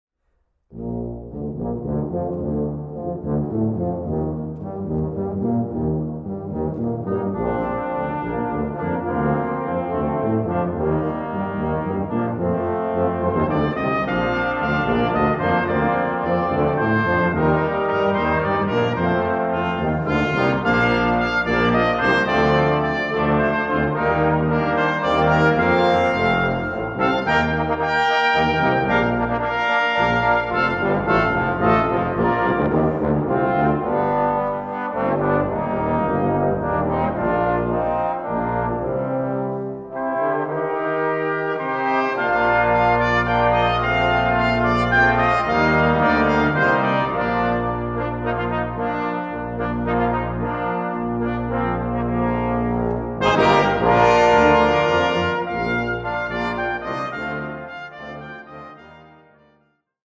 Fantasia for brass ensemble